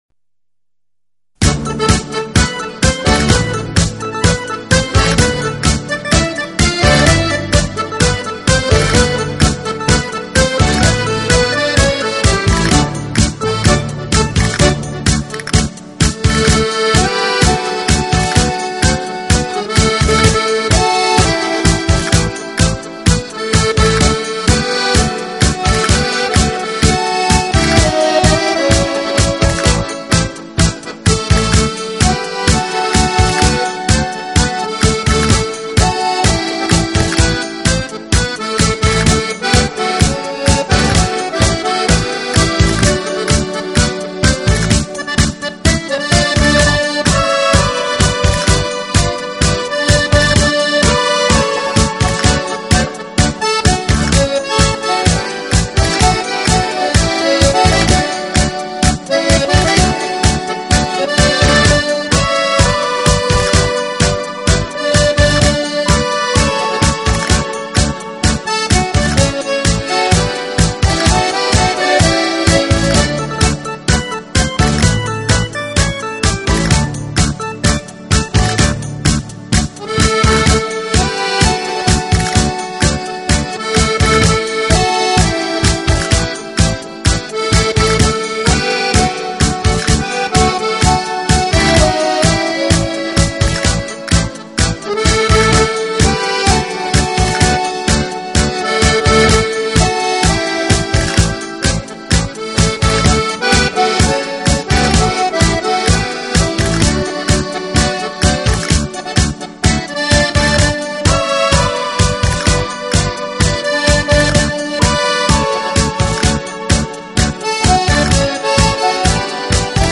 Genre: Instrumental
Channels: 44Khz, j.stereo